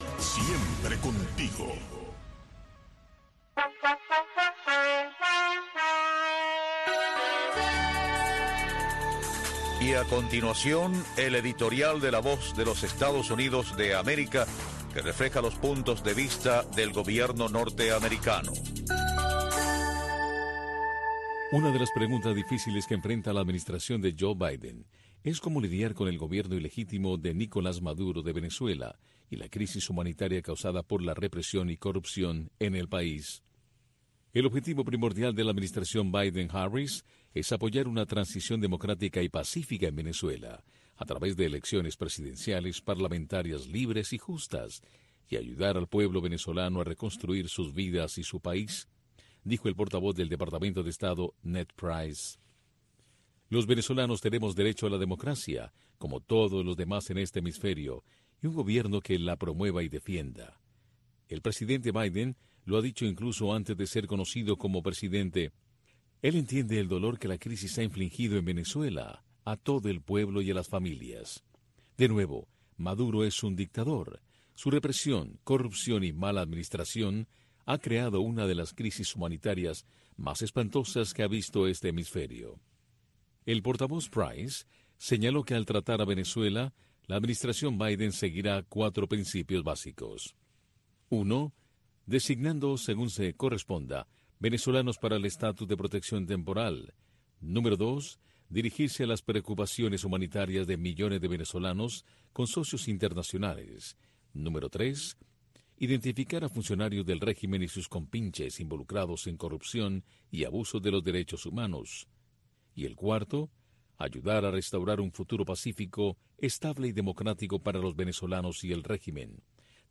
una revista de entrevistas